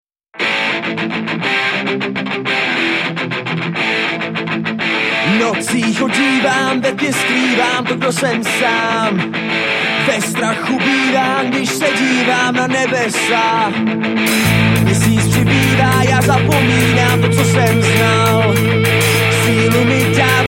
mladé klatovské punkové skupiny